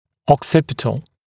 [ɔk’sɪpɪtl][ок’сипитл]затылочный